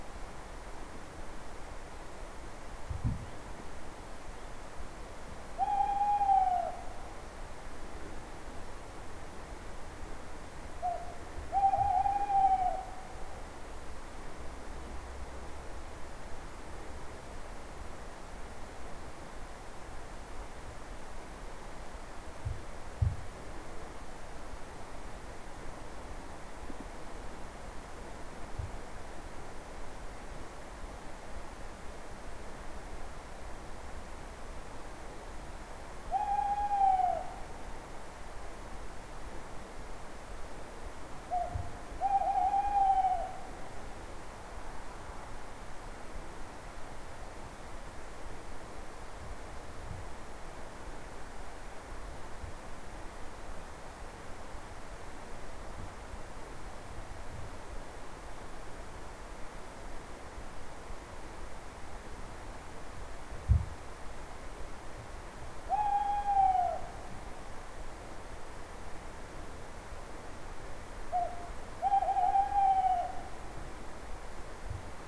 snowowl.wav